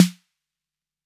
Snare 808 2.wav